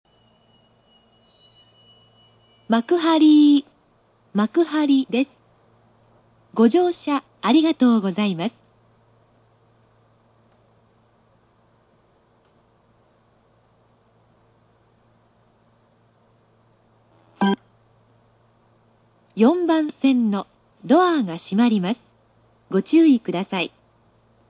スピーカー：National
ほとんどが即切りで、酷いのは